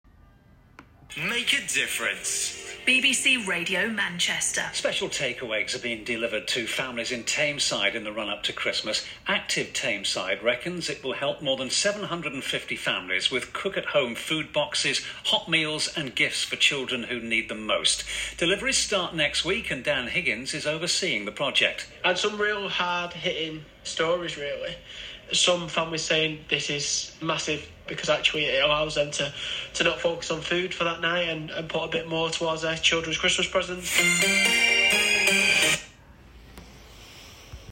Radio-Manchester-Christmas-takeaway.m4a